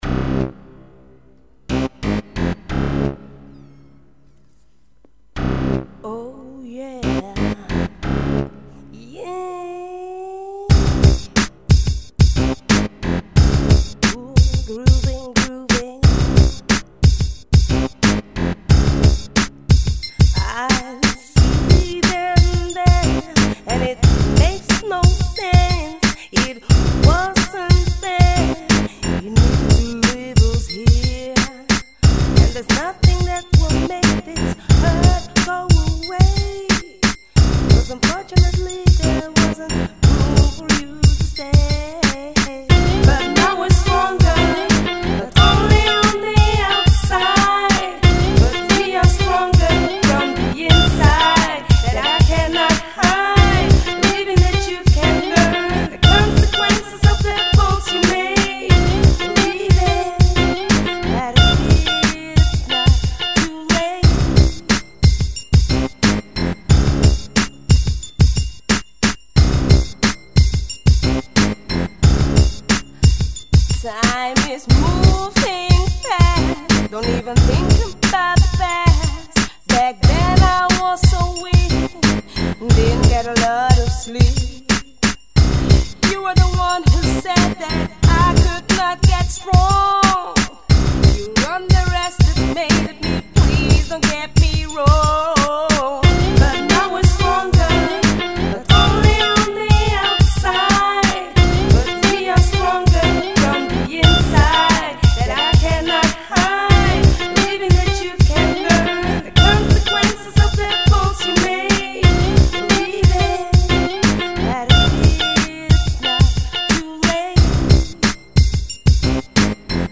Her Style can be described as R&B/Pop/ Soul